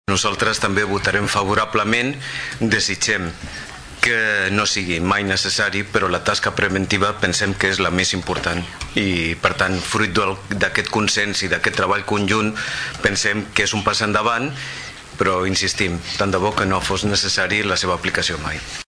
Des del PSC, el regidor Rafa Delgado considera positiva aquesta voluntat preventiva del protocol, però espera que no calgui aplicar-lo mai.